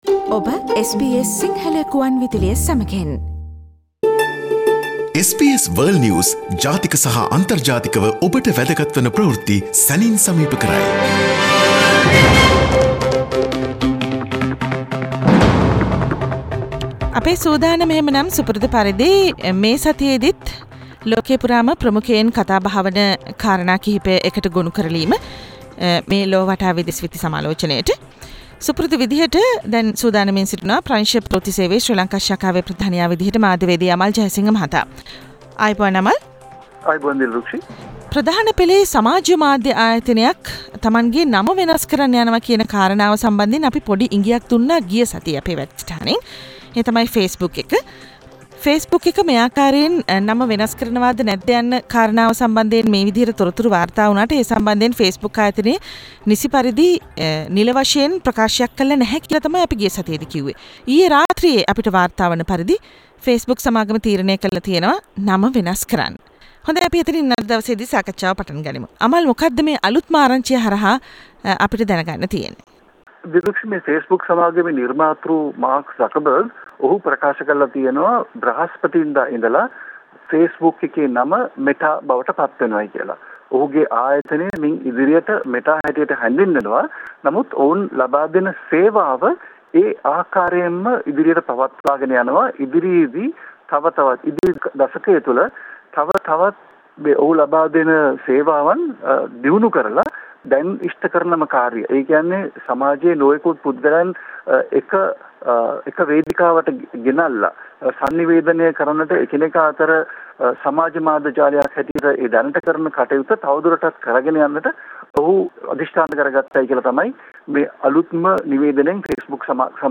SBS සිංහල සේවයේ සතියේ විදෙස් විත්ති සමාලෝචනය - "ලොව වටා" සෑම සිකුරාදා දිනකම ඔබ හමුවට.